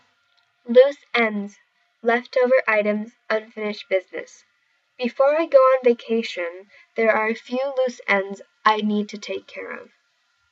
英語ネイティブによる発音は以下をクリックしてください。